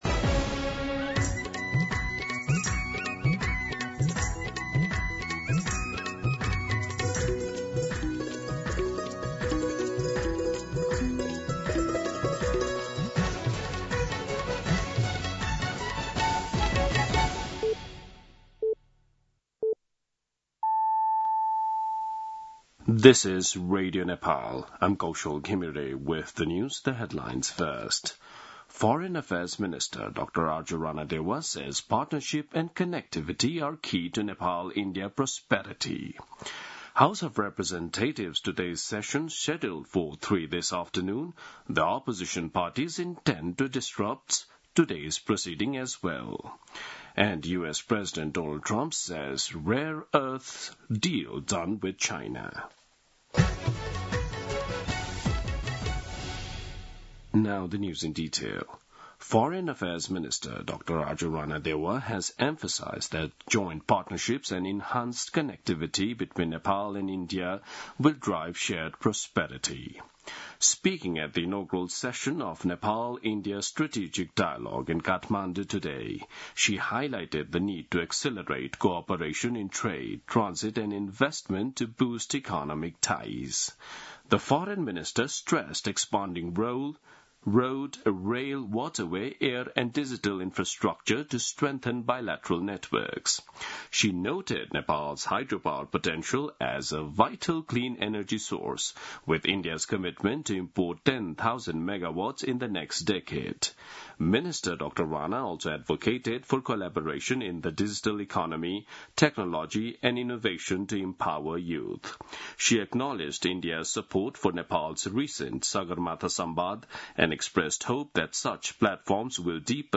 दिउँसो २ बजेको अङ्ग्रेजी समाचार : २९ जेठ , २०८२
2-pm-English-News.mp3